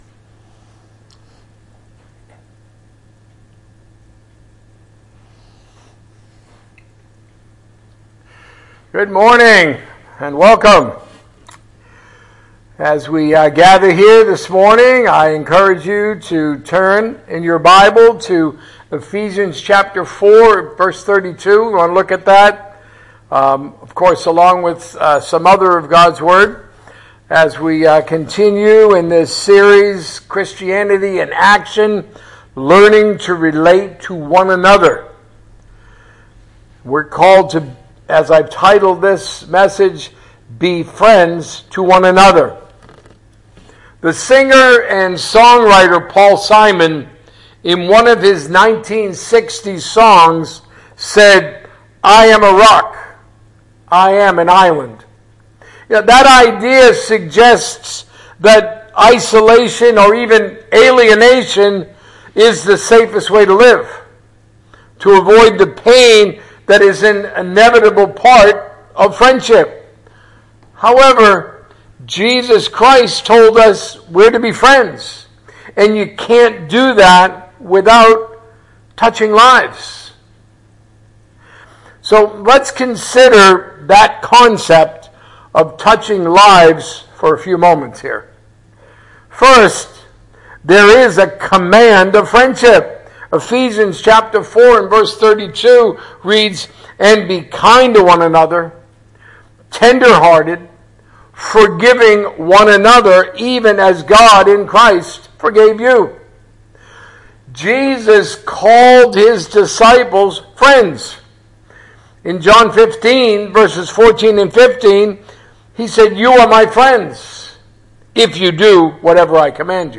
A message from the series "The Church."